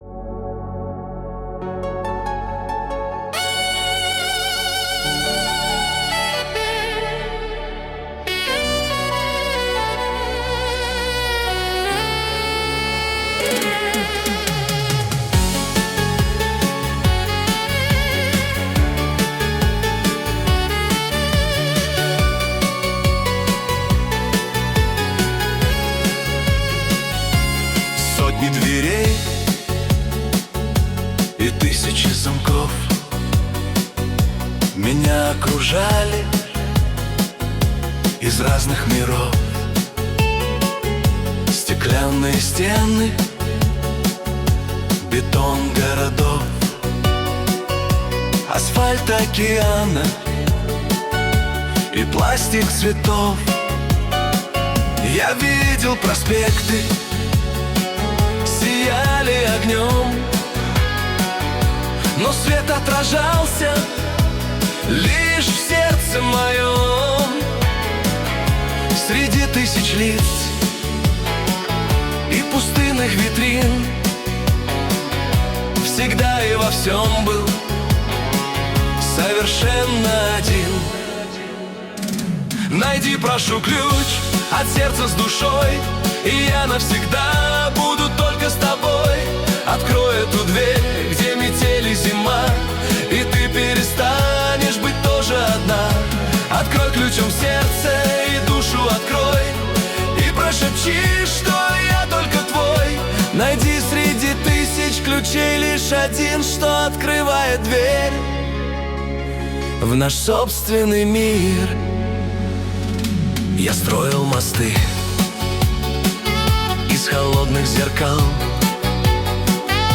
Качество: 320 kbps, stereo
Поп музыка, Dance pop